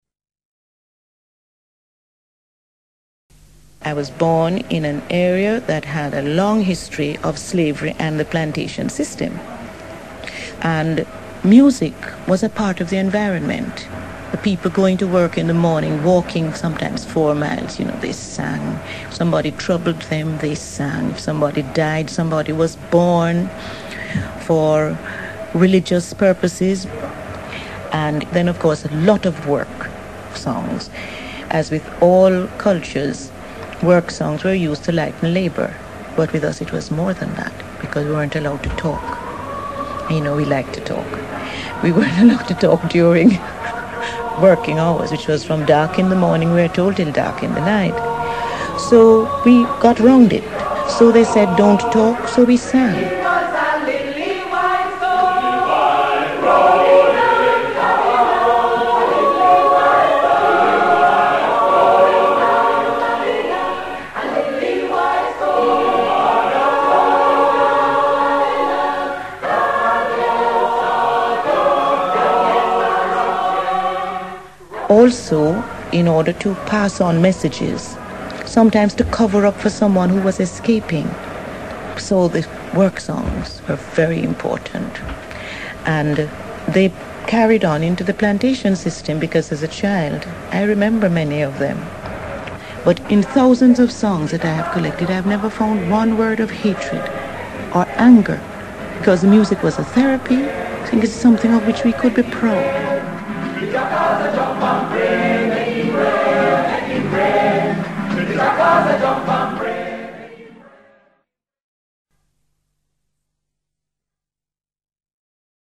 A Jamaican Accent
A native of Jamaica talks about songs
La diferencia más perceptible entre ambas modalidades de inglés reside en que en el inglés jamaicano la vocal débil /ə/ se utiliza con mucha menos frecuencia que en inglés
Por esta causa, no se produce un contraste fuerte entre las sílabas acentuadas y las no acentuadas, y el habla jamaicana adquiere un tono uniforme.
JAMAICA-OchoRios.mp3